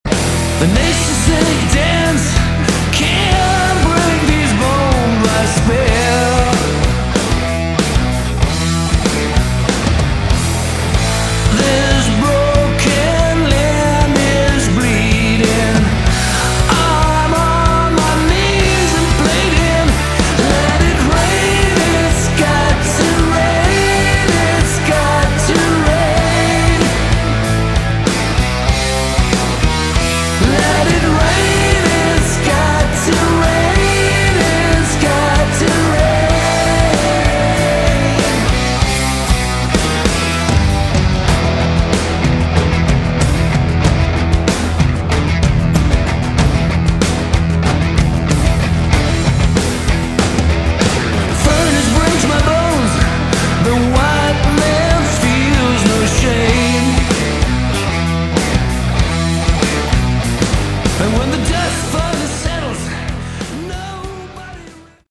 Category: AOR
lead and backing vocals
guitar, backing vocals, keyboards, bass
drums, backing vocals
lapsteel